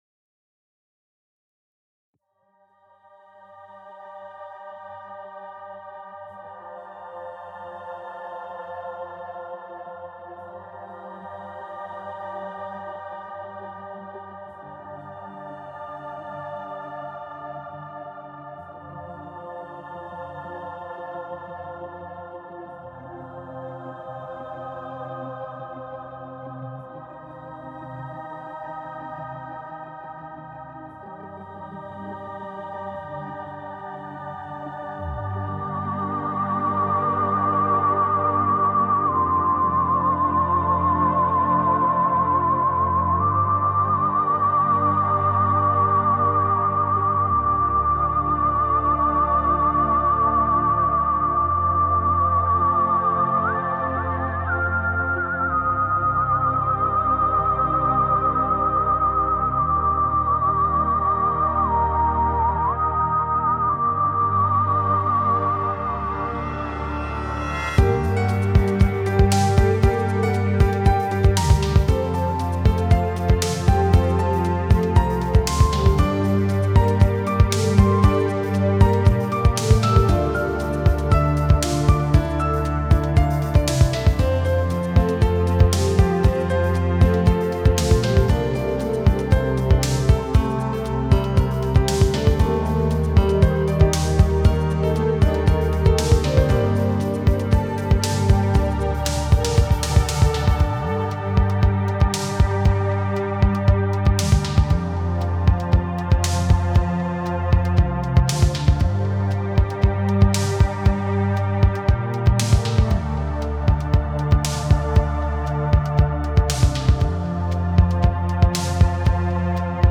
Genre Melodic